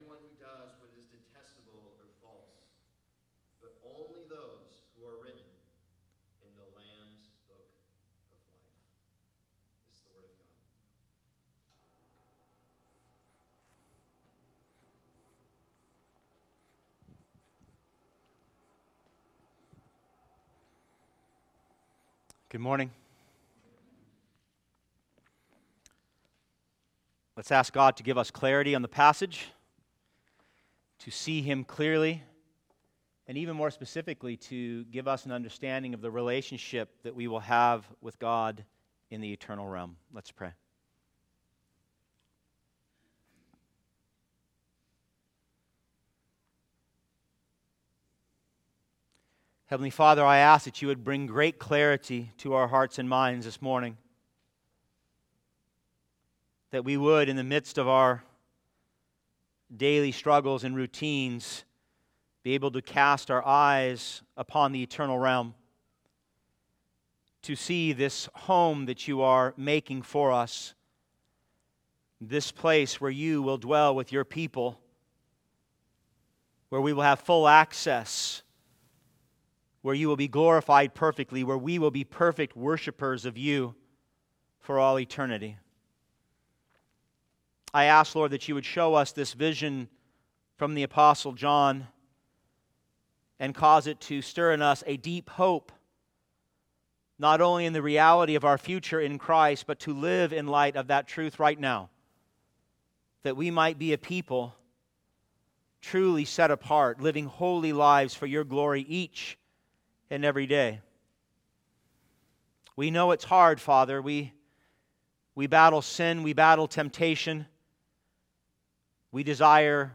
preaching on Revelation 21:22-27.